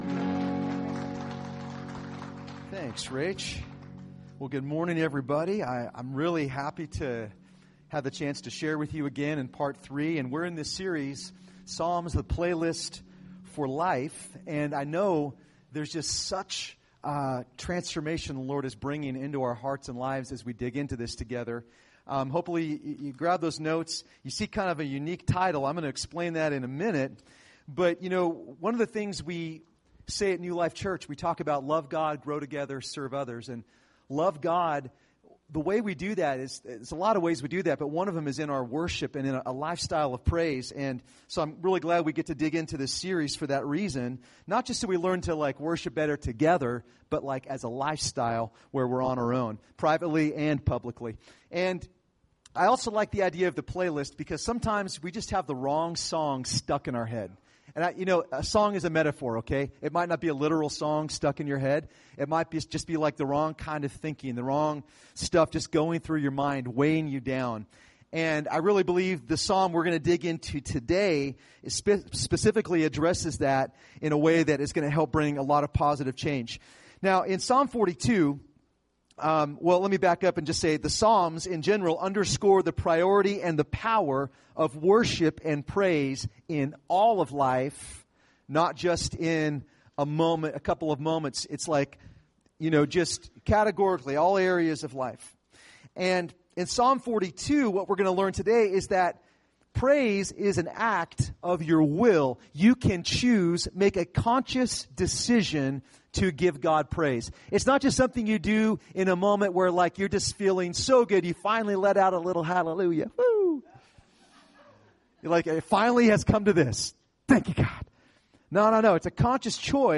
Recorded at New Life Christian Center, Sunday, September 23, 2018 at 9 AM.